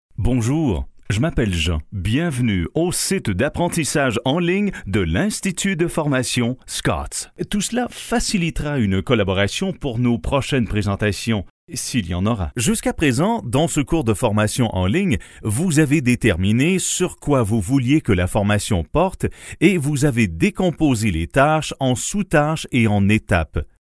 Authentic french canadian male voice
Sprechprobe: eLearning (Muttersprache):